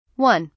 mono_16khz.mp3